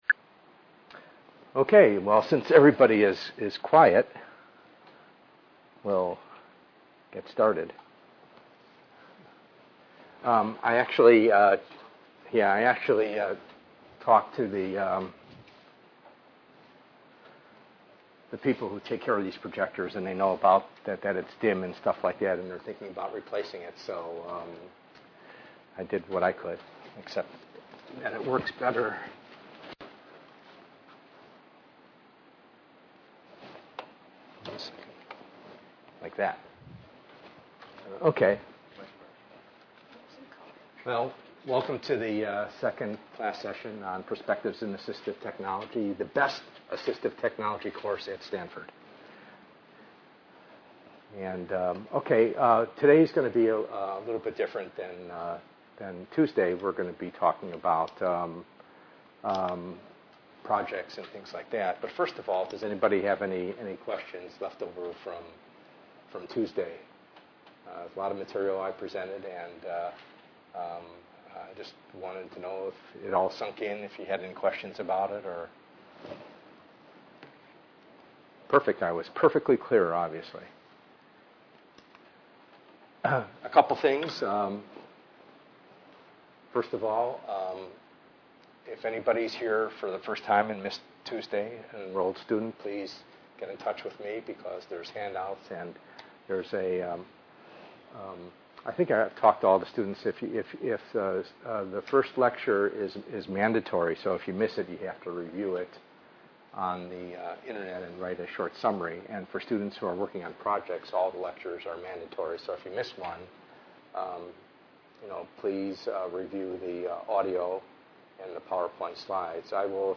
ENGR110/210: Perspectives in Assistive Technology - Lecture 1b